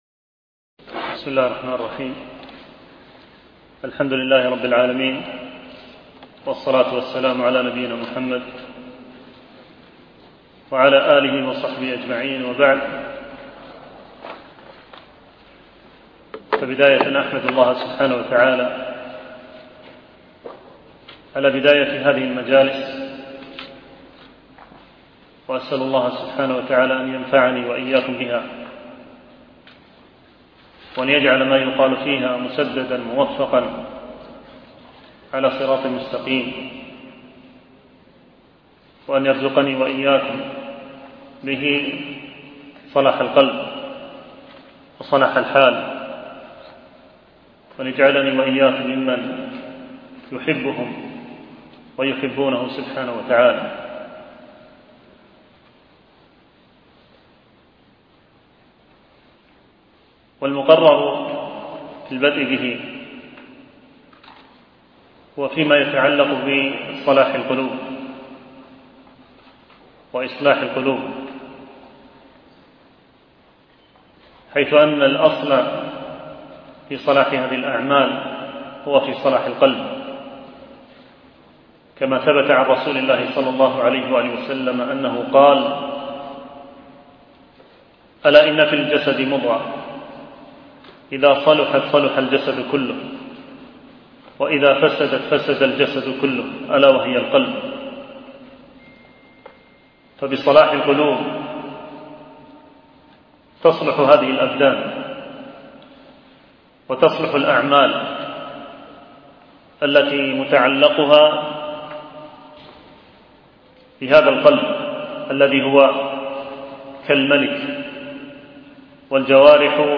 شرح الرسالة التبوكية - الدرس الأول